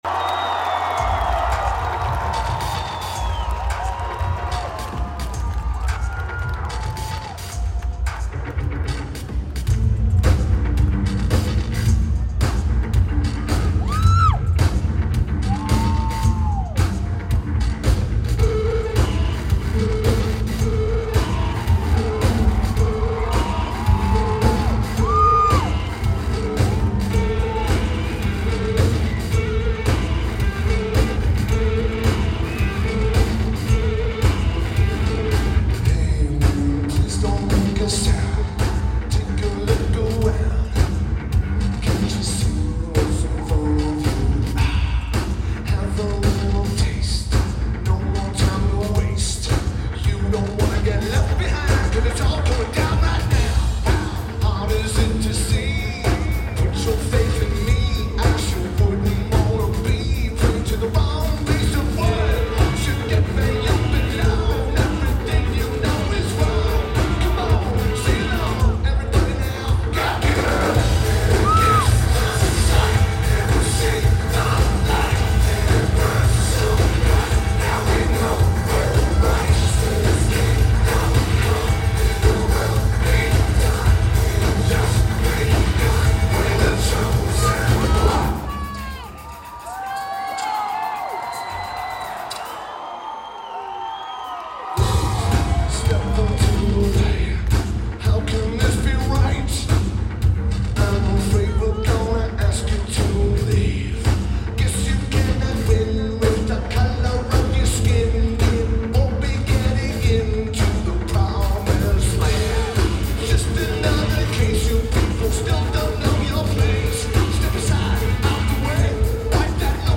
Knoxville Civic Coliseum
Keyboards/Bass/Backing Vocals
Drums
Guitar
Vocals/Guitar/Keyboards